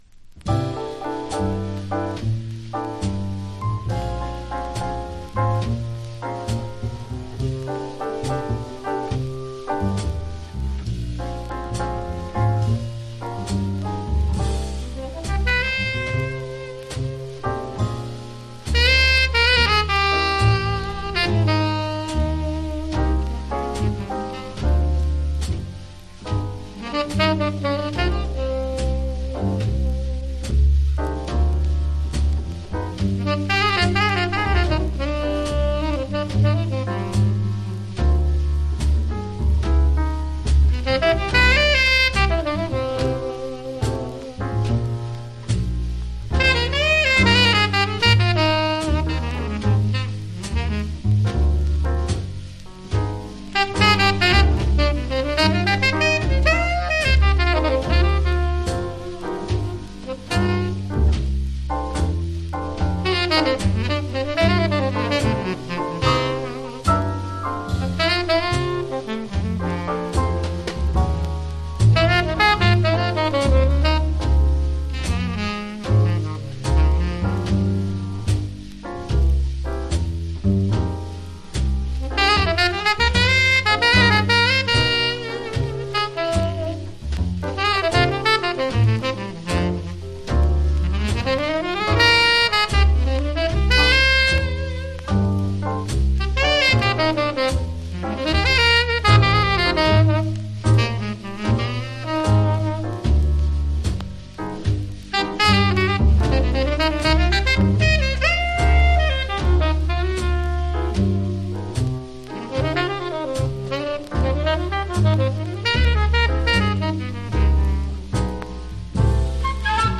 （小傷によりチリ、プチ音ある曲あり）(盤面スレ、小傷多いですがMONO針で聴くと良好）
Genre US JAZZ